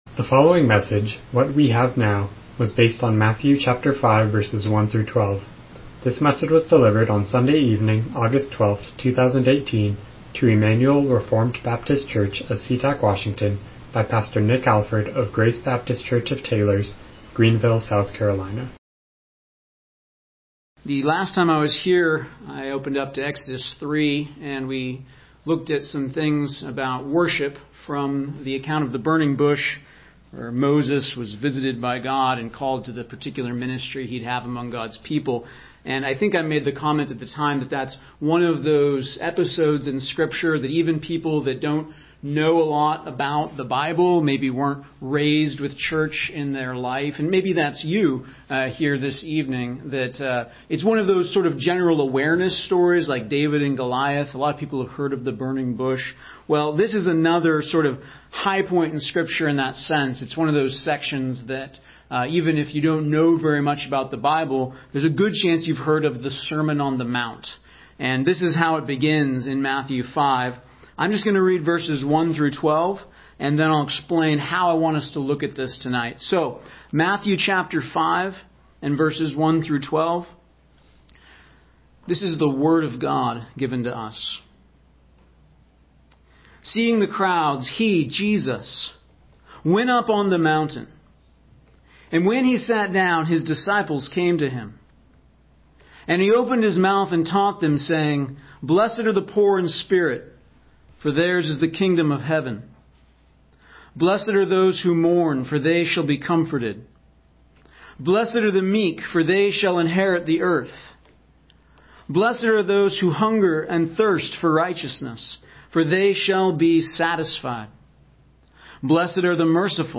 Miscellaneous Passage: Matthew 5:1-12 Service Type: Evening Worship « Worship